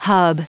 Prononciation du mot hub en anglais (fichier audio)
Prononciation du mot : hub